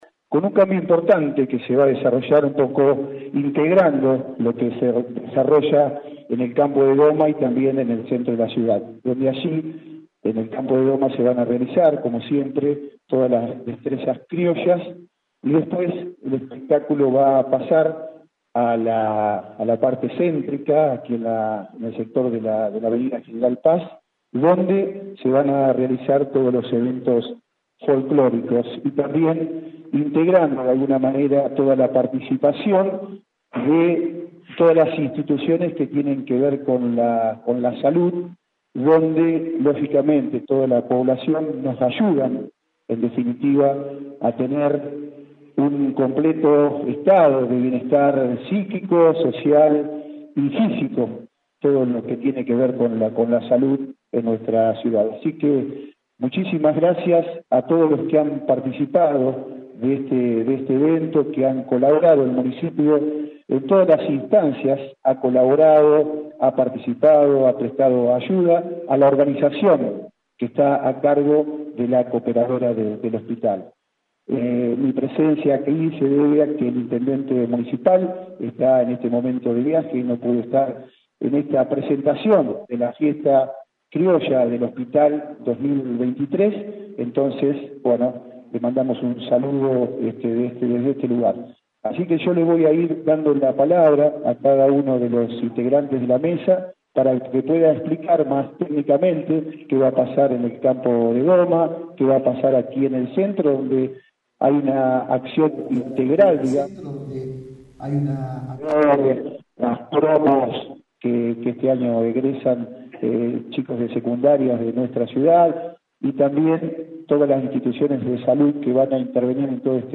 Este fin de semana se realizará la edición 2023 de la Fiesta Criolla a beneficio del Hospital Zonal General, con la organización de la Cooperadora del nosocomio y el acompañamiento de la Municipalidad de Las Flores. En la mañana de este miércoles, el presidente del Concejo Deliberante, Fabián Blanstein, encabezó una conferencia de prensa en el Salón Rojo del Palacio Municipal donde se brindaron detalles del evento que tendrá lugar el sábado 22 y domingo 23.
Conferencia-Fiesta-Criolla.mp3